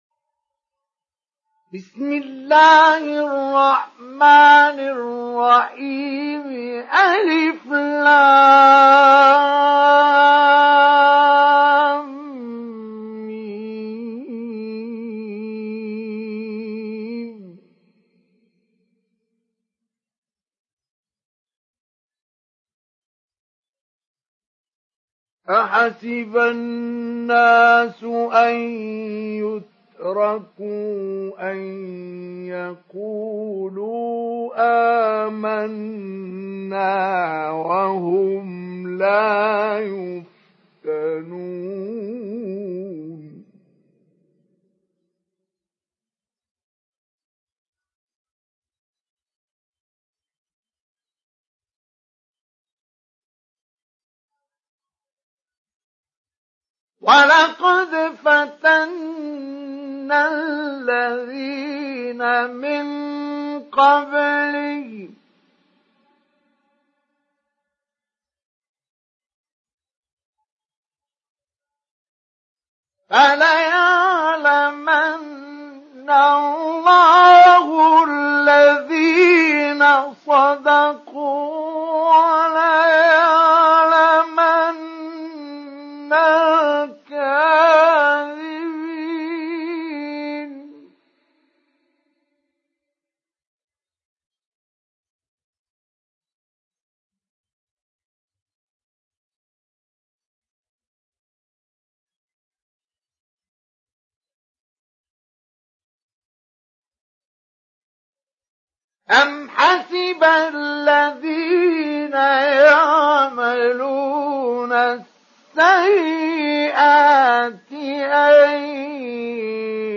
ডাউনলোড সূরা আল-‘আনকাবূত Mustafa Ismail Mujawwad